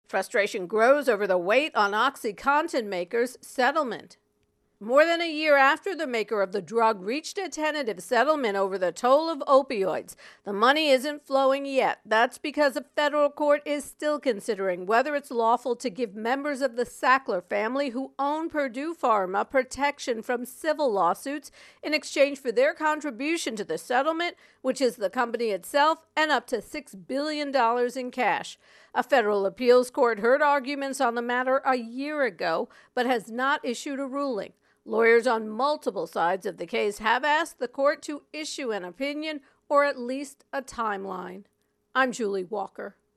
reports on Opioid Crisis Purdue Bankruptcy